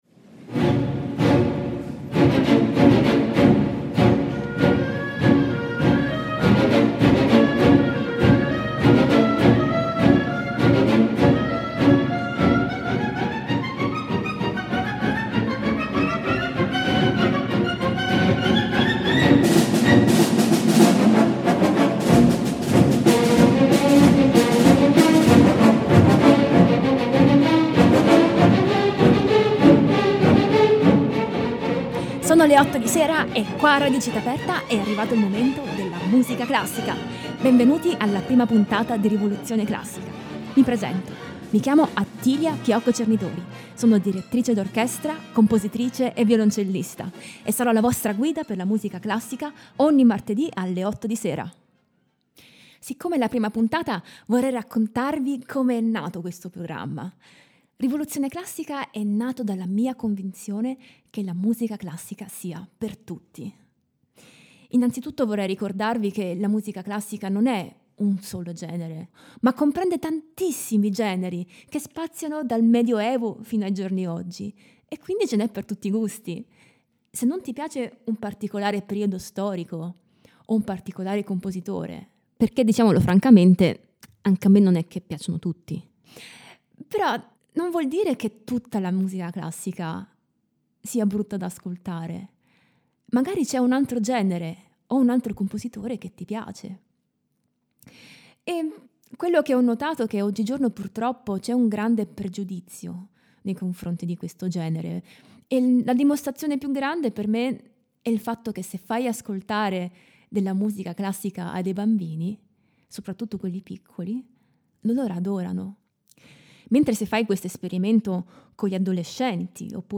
Prima puntata della trasmissione dedicata alla musica classica
-Genesi del programma e perche’ rivoluzione classica? -Intervista